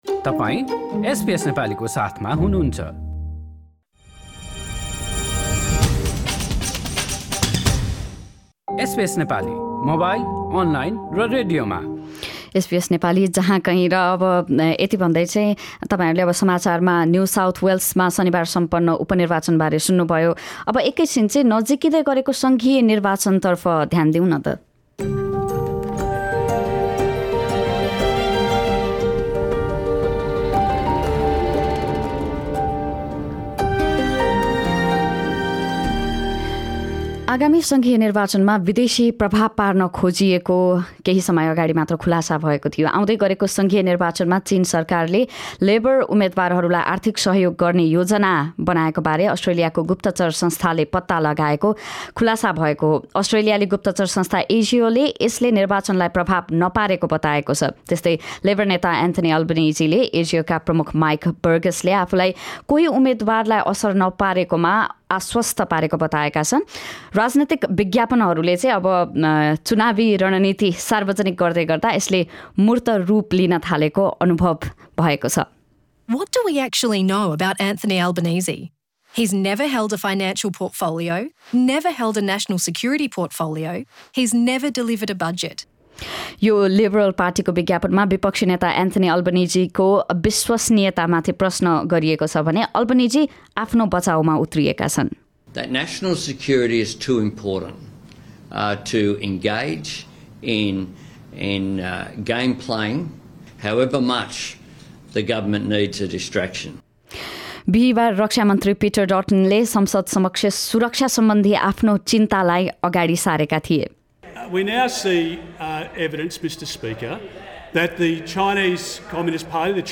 अल्बनिजीको भनाइ सहितको रिपोर्ट यहाँ सुन्नुहोस्: अस्ट्रेलियाको आउँदो सङ्घीय निर्वाचनमा विदेशी प्रभाव पार्न खोजिएको दाबीको खुलासा हाम्रा थप अडियो प्रस्तुतिहरू पोडकास्टका रूपमा उपलब्ध छन्।